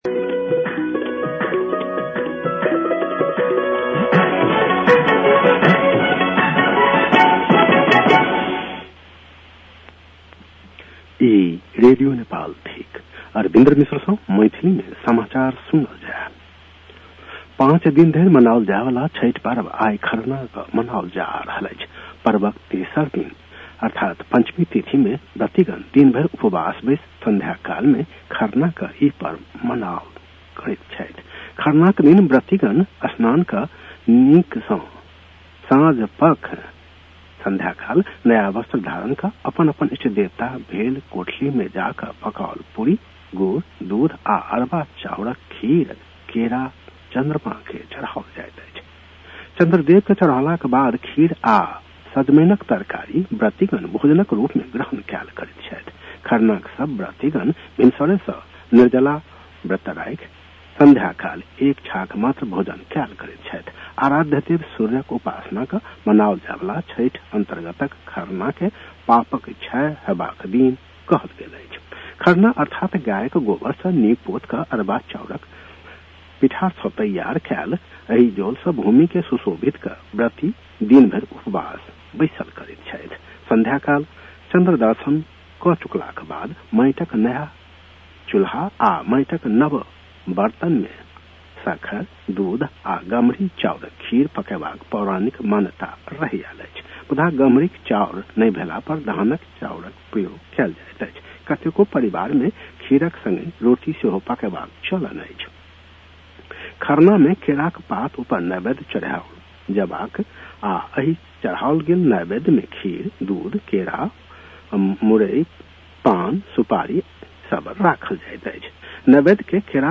मैथिली भाषामा समाचार : ९ कार्तिक , २०८२
6.-pm-maithali-news-1-6.mp3